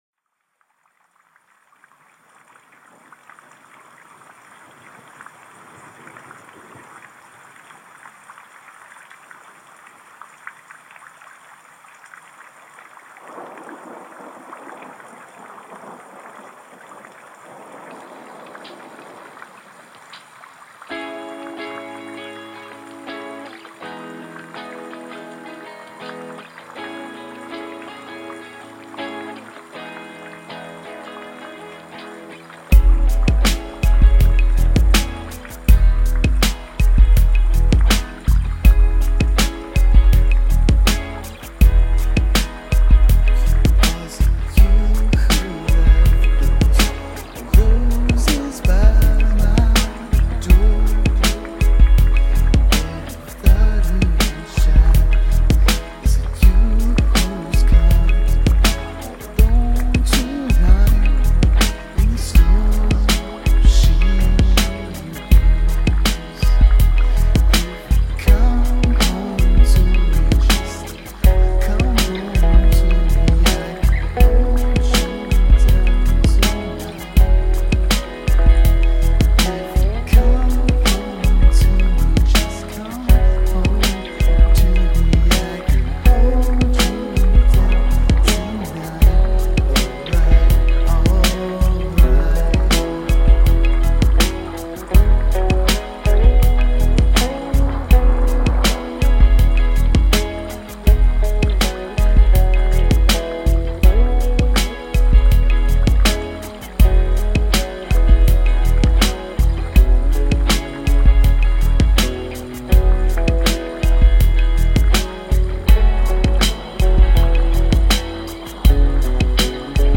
Feel that bass man, feel it. https